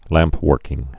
(lămpwûrkĭng)